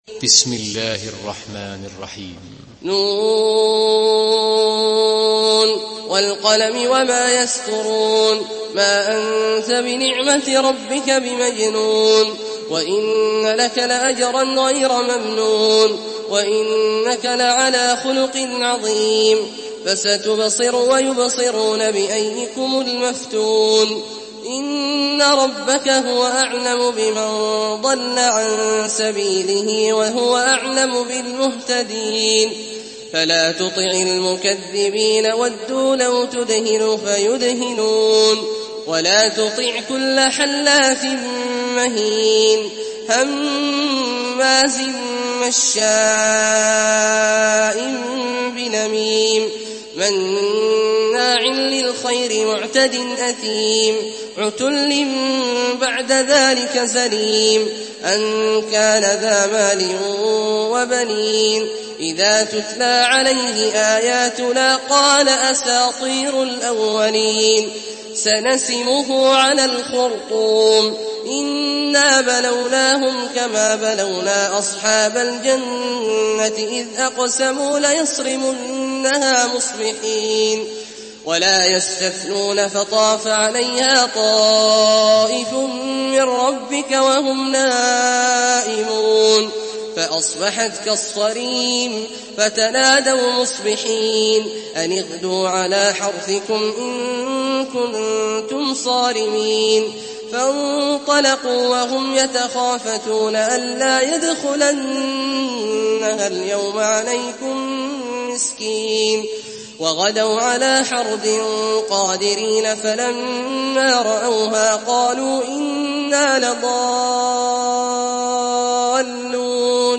سورة القلم MP3 بصوت عبد الله الجهني برواية حفص
مرتل